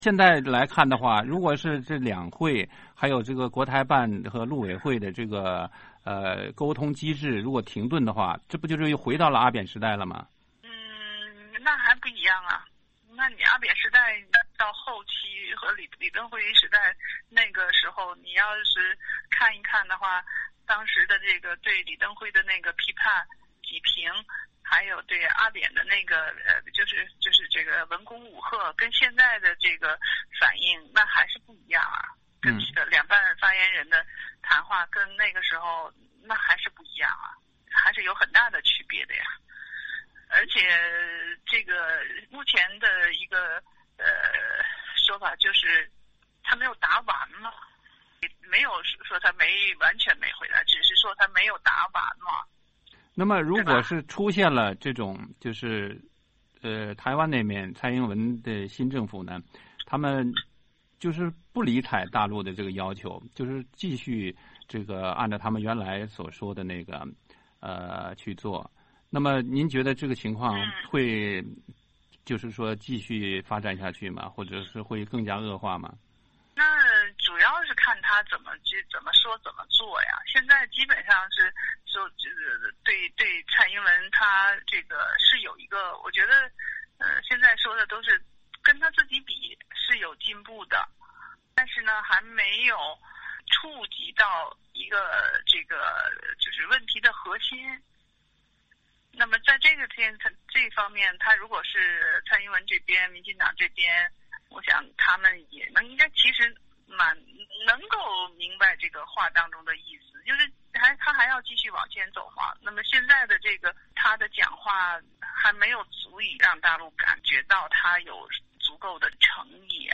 （据电话录音整理）